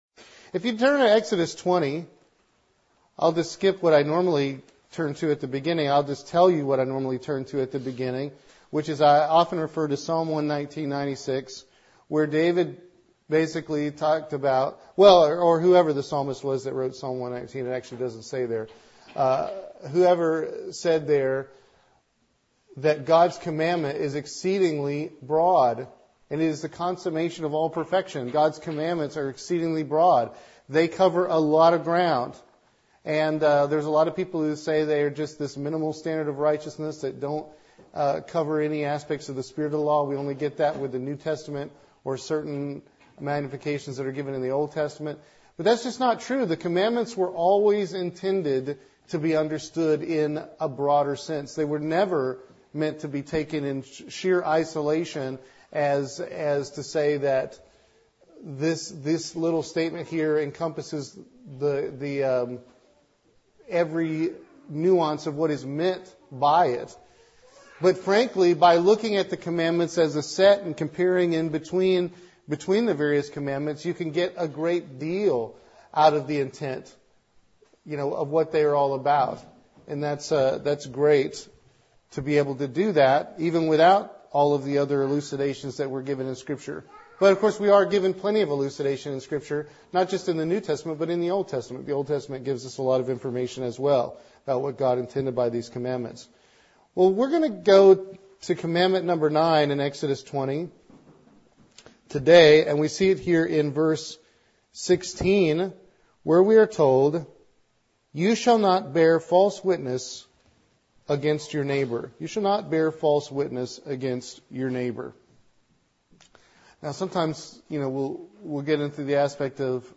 This sermon takes a look at the deep spiritual meaning of the 9th commandment.